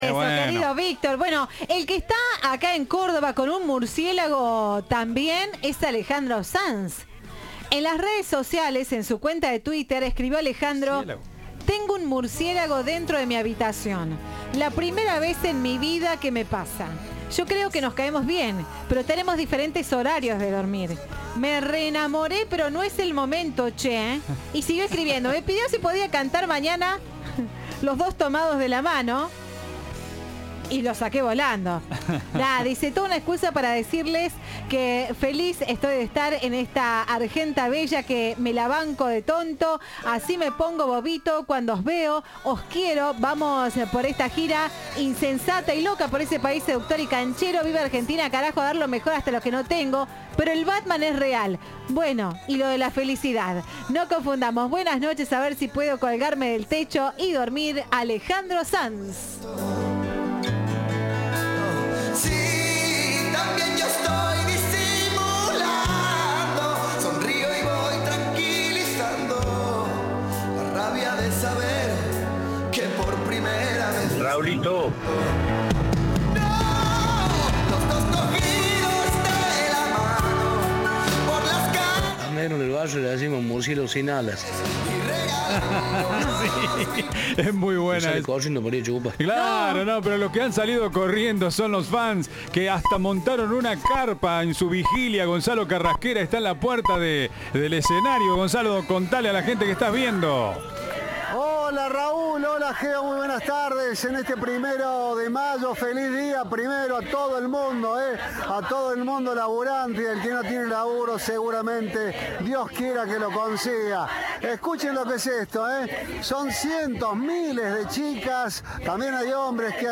“Vine desde Bariloche exclusivamente a verlo a él. Desde que compré la entrada empecé a hacer cuenta regresiva hasta hoy. No lo puedo creer”, dijo a Cadena 3 una de las asistentes.